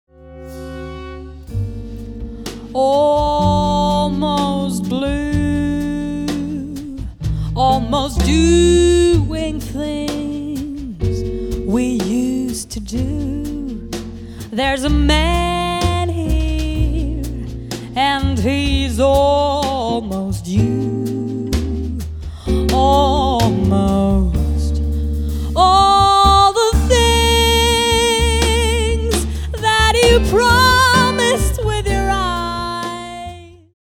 Jazz Sängerin & Songwriterin